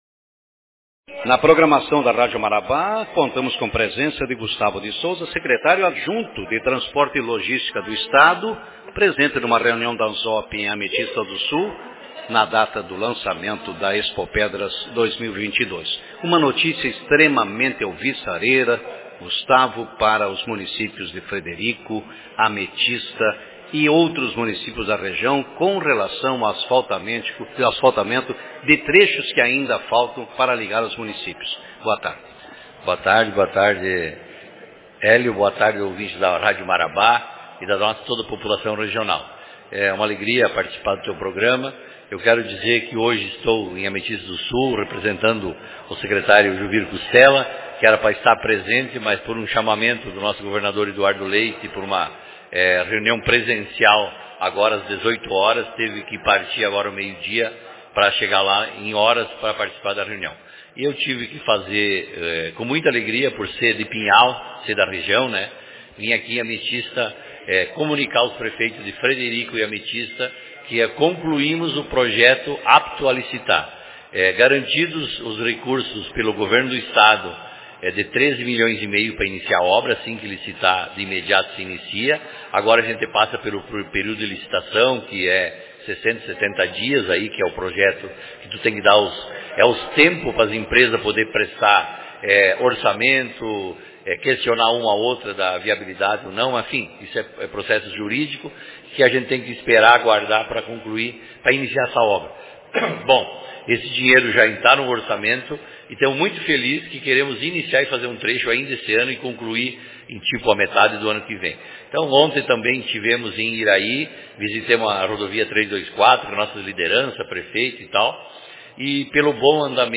Governo do Estado anuncia pavimentação asfáltica da ERS 591 entre Frederico Westphalen e Ametista do Sul Autor: Rádio Marabá 18/03/2022 Manchete Durante a reunião de prefeitos promovida ontem, 17, pela Associação dos Municípios da Zona da Produção – AMZOP, a Expopedras em Ametista do Sul, o Secretário Adjunto da Secretaria de Logística e Transportes do Estado, Luiz Gustavo de Souza, anunciou a aprovação do projeto de asfaltamento do trecho da ERS 591 entre Frederico Westphalen e Ametista. Na ocasião, o representante do governo gaúcho falou sobre o assunto.